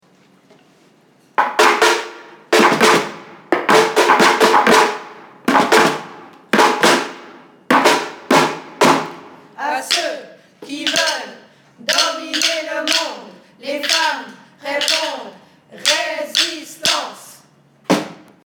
Rhythmen und Slogans zur Demo
Rhythmen und Slogans zum Marche Mondiale des Femmes